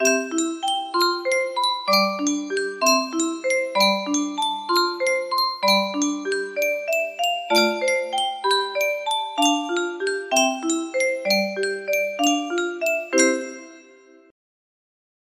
Clone of Yunsheng Spieluhr - Friesenlied Y311 music box melody